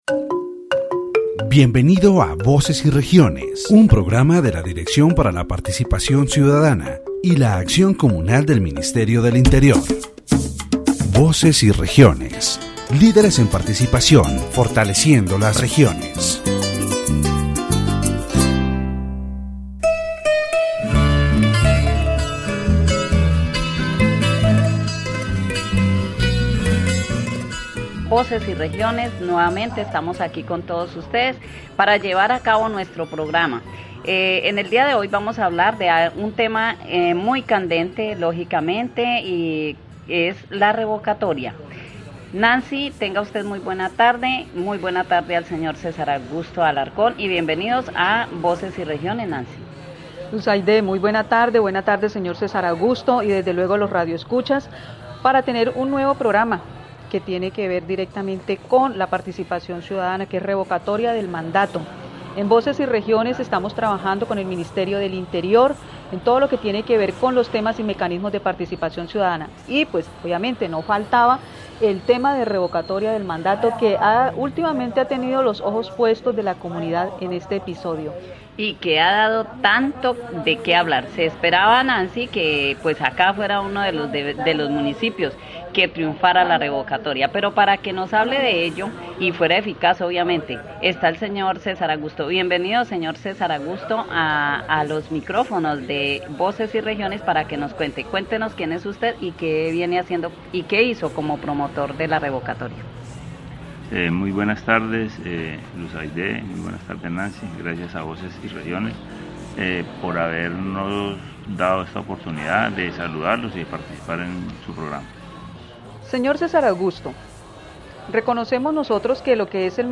The radio program "Voces y Regiones" of the Ministry of the Interior is broadcast from Florencia, Caquetá, on station 98.1. In this episode, the issue of revoking the mandate, a mechanism for citizen participation, is addressed.